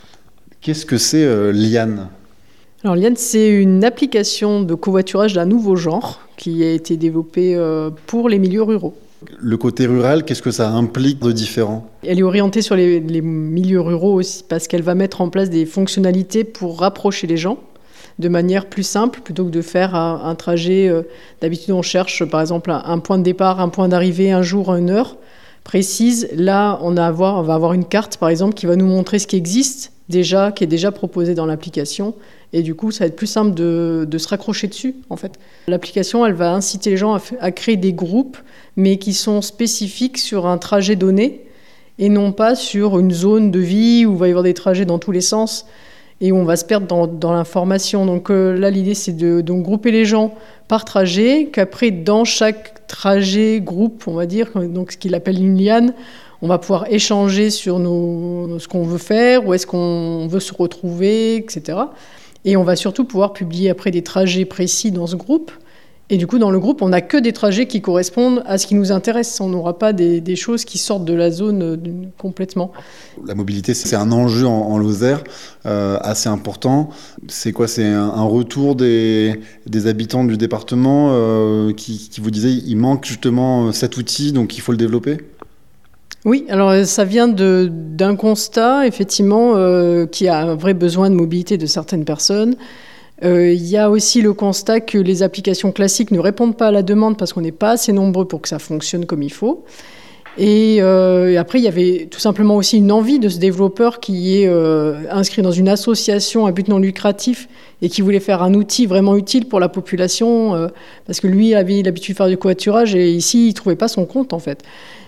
ITV_LIANE.mp3